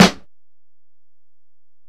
Snare (33).wav